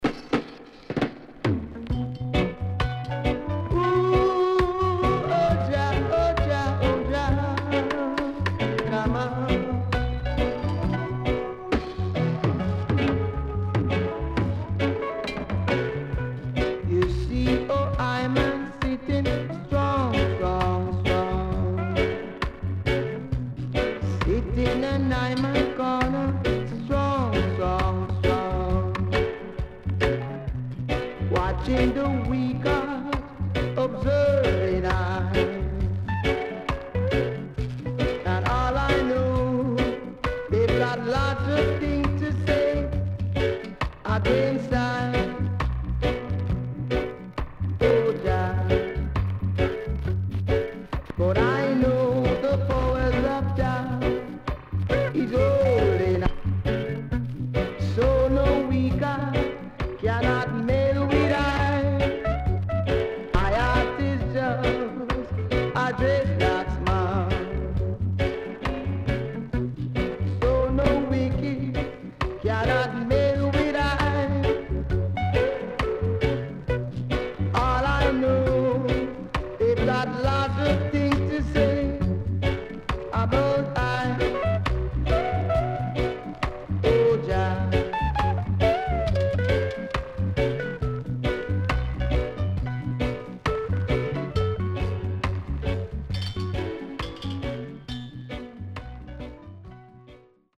HOME > LP [VINTAGE]  >  KILLER & DEEP
SIDE A:少しノイズ入りますが良好です。
SIDE B:少しノイズ入りますが良好です。